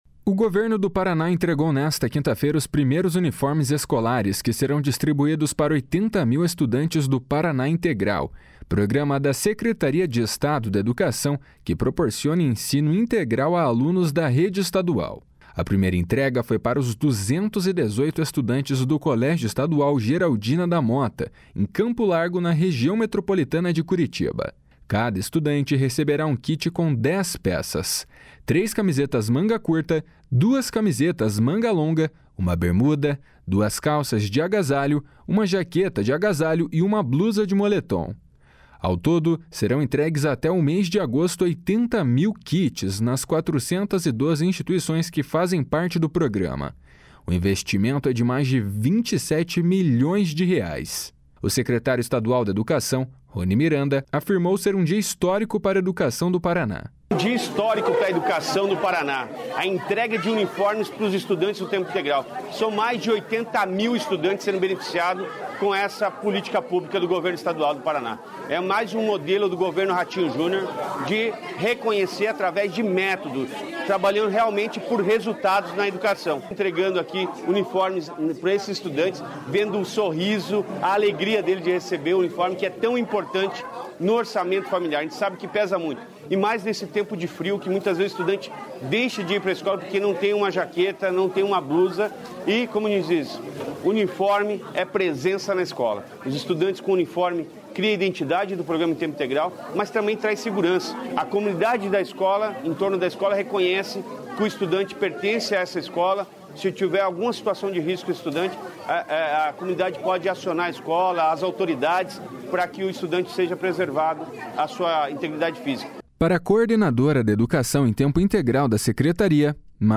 O secretário estadual da Educação, Roni Miranda, afirmou ser um dia histórico para a educação do Paraná.